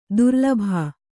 ♪ durlabhā